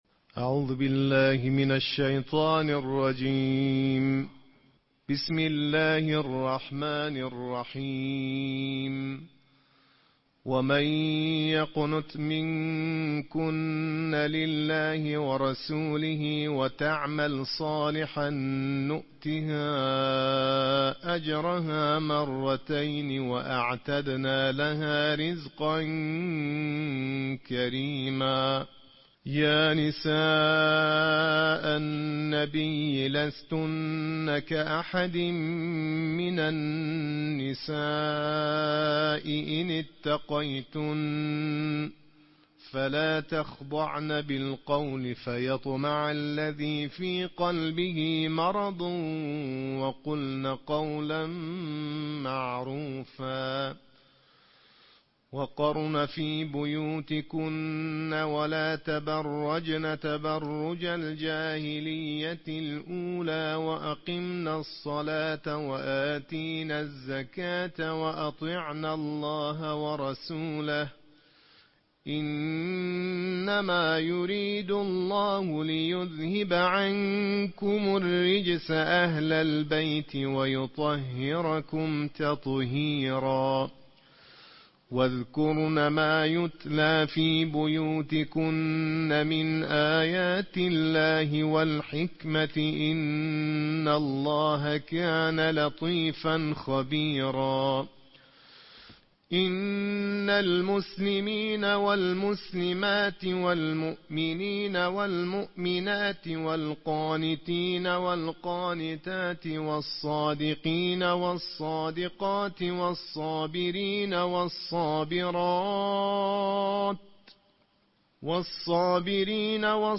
نړیوال قارِيان ،د قرآن کریم د دوویشتمې(۲۲) سپارې یا جزوې د ترتیل قرائت